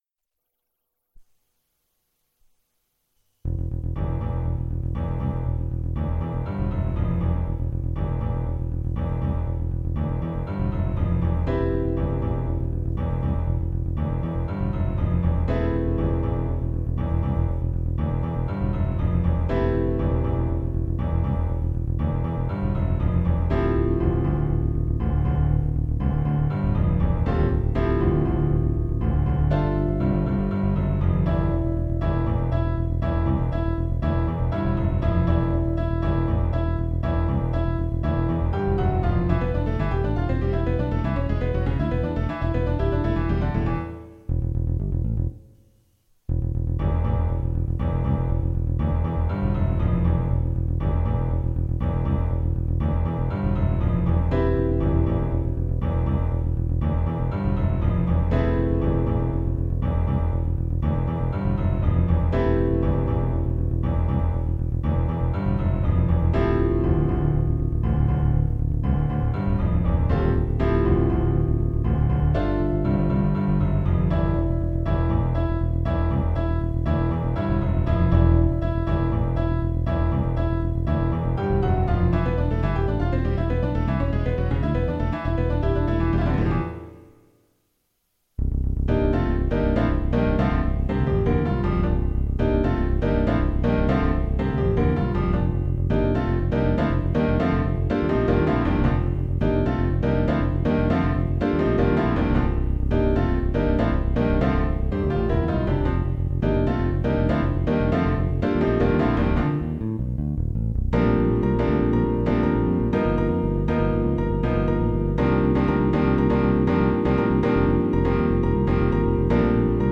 Type: Electronically Generated Location: Konstanz Date: 2007